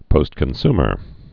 (pōstkən-smər)